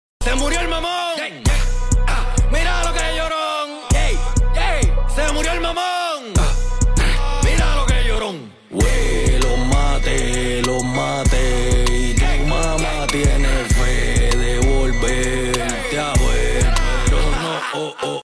Weeee sound effects free download